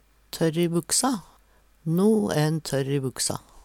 tørr i buksa - Numedalsmål (en-US)